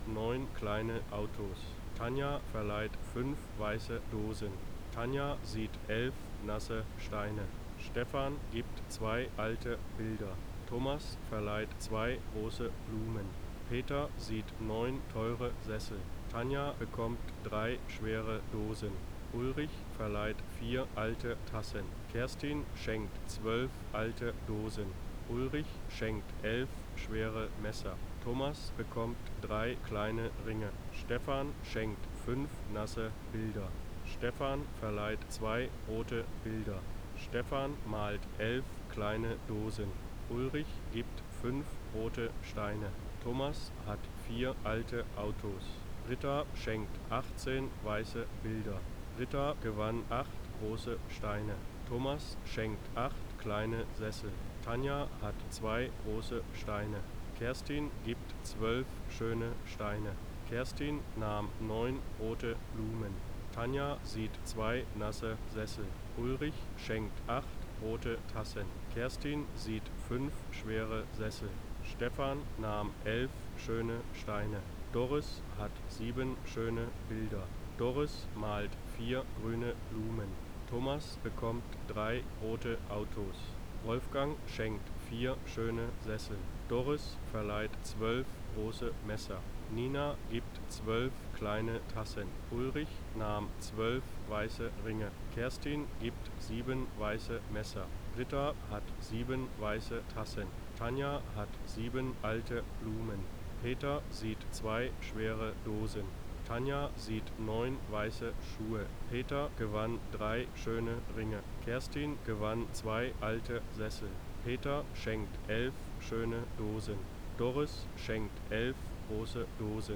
Rauschen mit Sprecher versch_SNR_R45  S54.wav